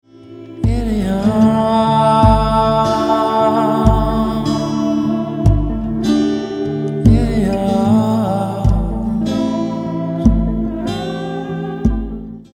Bass-y Plucking